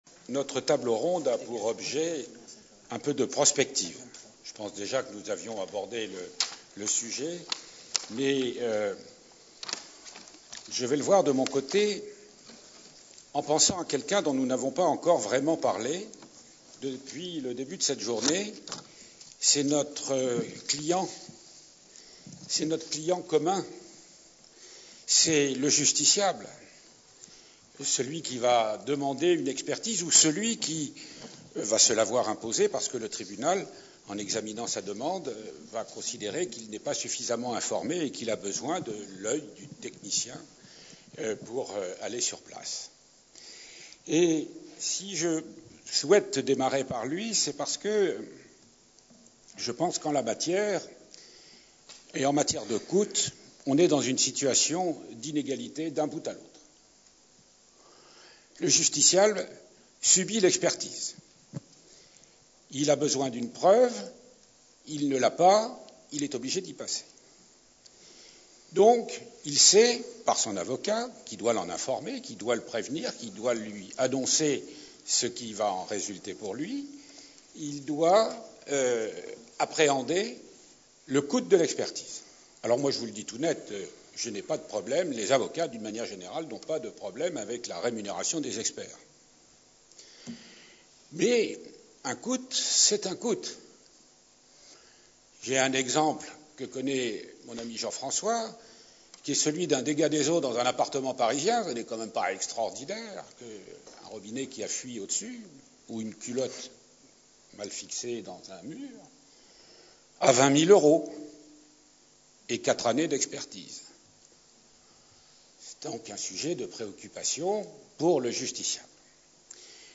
Les coûts de l’expertise de Justice Reims 2014 : Table ronde "Prospectives et synthèse" | Canal U
Conférence enregistrée lors du 7ème Colloque de la Compagnie des Experts de Reims.